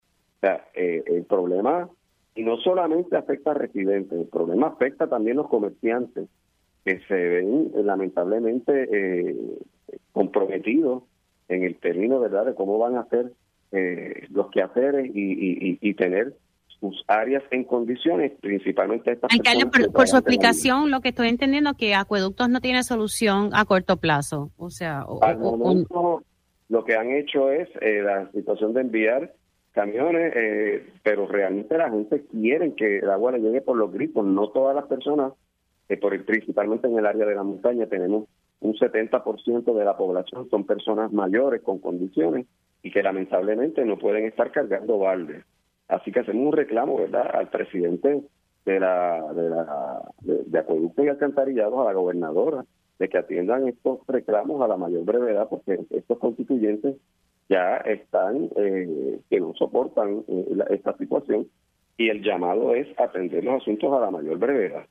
El alcalde de Hatillo, Carlos Román denunció en Pega’os en la Mañana que varias zonas en su municipio – incluyendo Buena Vista, Mariposa, Campo Alegre, Carrizales, Aibonito y Bayané – tienen más de 70 días sin agua potable.